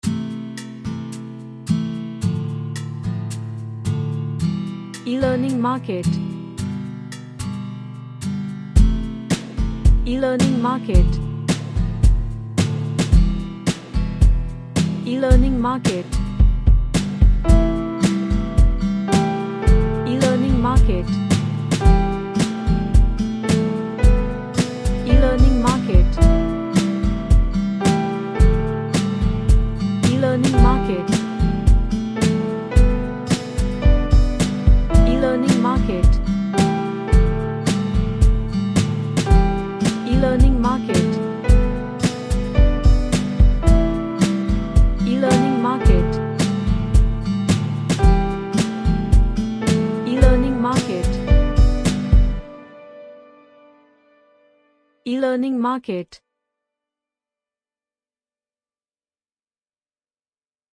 A Happy acoustic track with playful vibes.
Happy